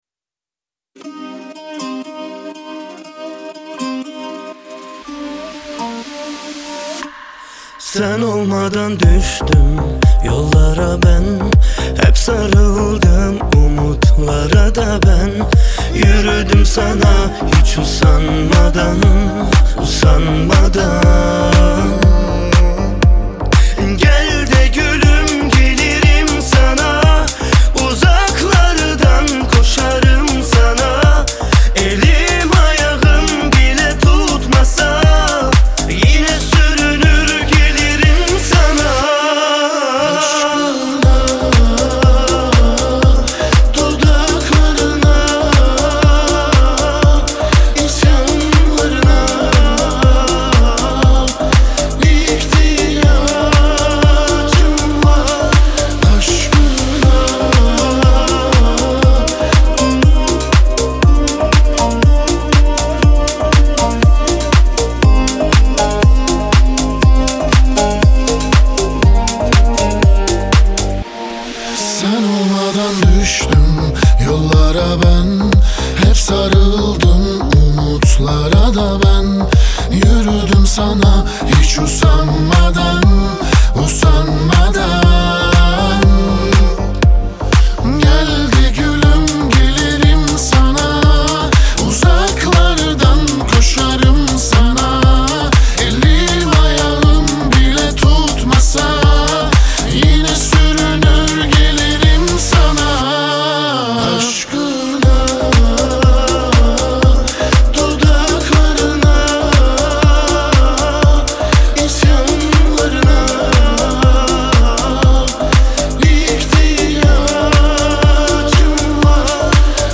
dance
диско